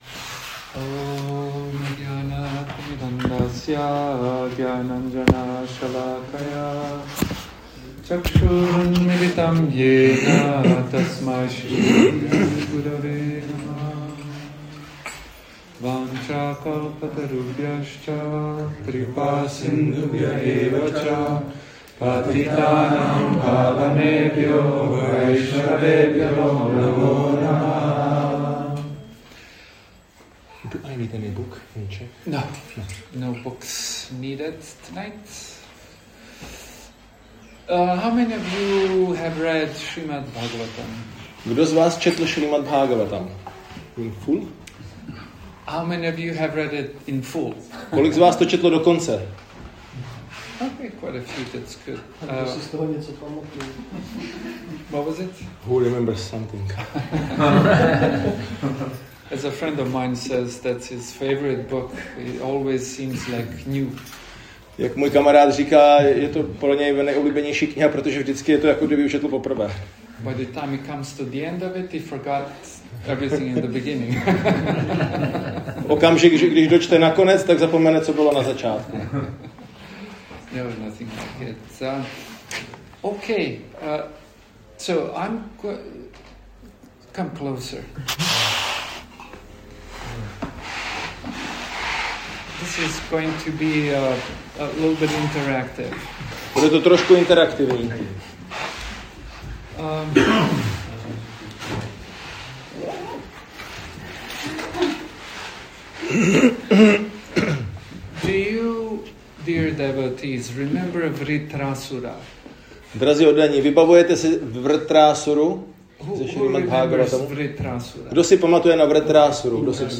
Jak nás životní zvraty mohou povznést k transcendenci | Přednášky | Audio | Prabhupád Bhavan